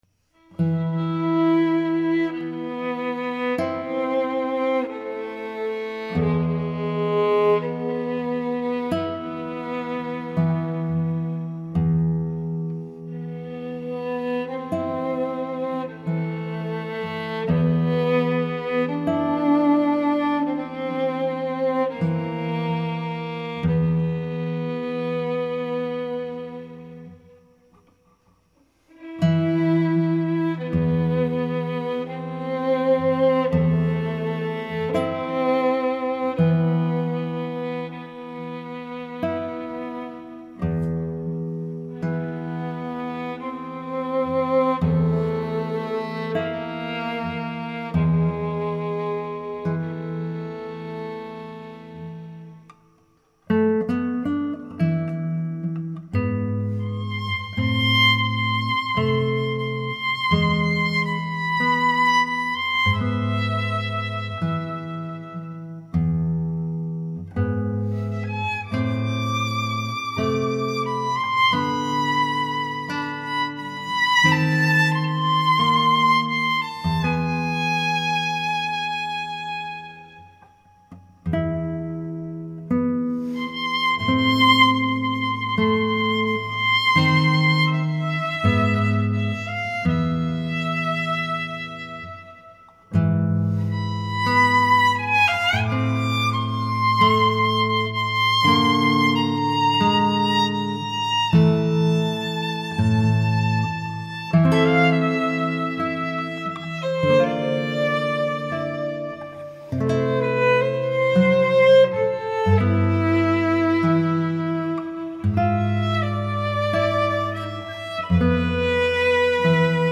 Violin & Guitar
violin
guitar